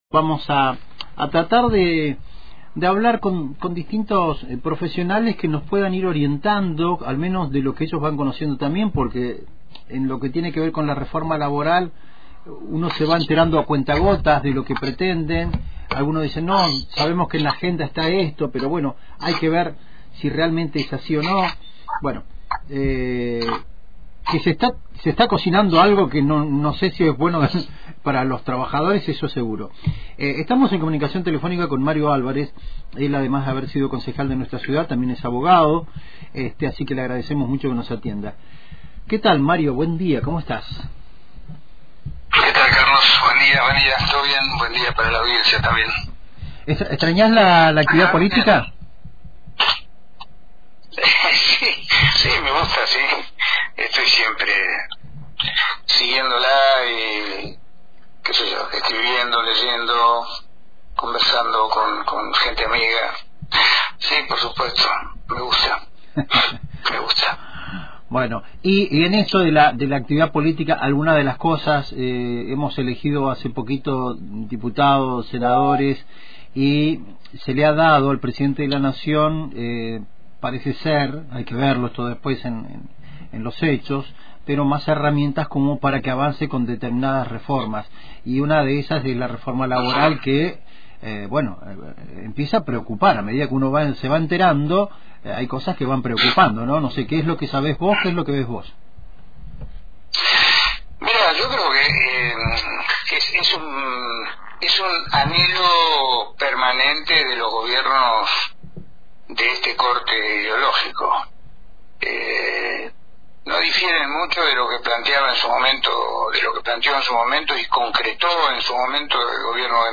En diálogo con Radio Antena Libre, el abogado y ex concejal Mario Álvarez analizó el proyecto de reforma laboral impulsado por el gobierno nacional, advirtiendo que repite los errores de los años 90.